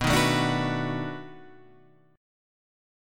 B7#9 chord {7 6 7 7 7 7} chord